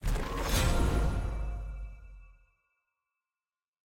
sfx_ui_map_vfx_godray.ogg